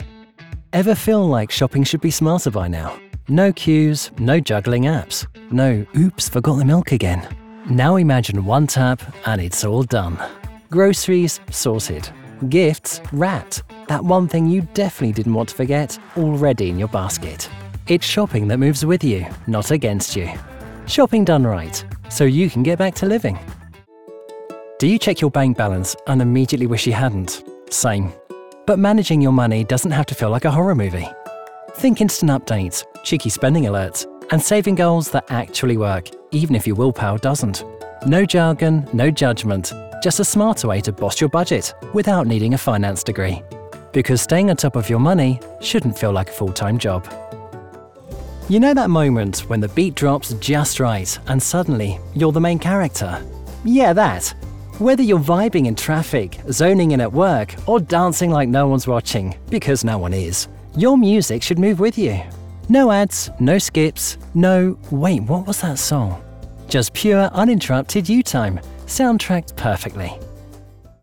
Comercial, Natural, Llamativo, Amable, Joven, Empresarial
Explicador